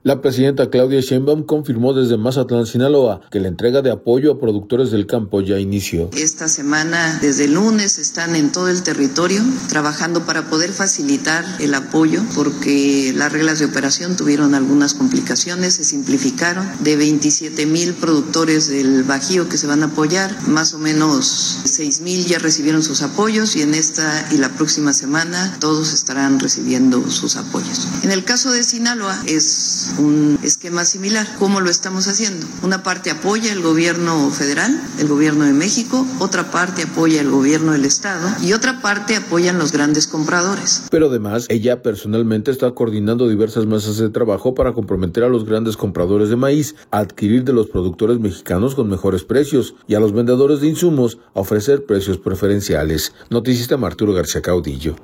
La presidenta Claudia Sheinbaum confirmó desde Mazatlán, Sinaloa, que la entrega de apoyos a productores del campo ya inició.